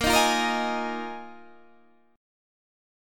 Bb7sus4 Chord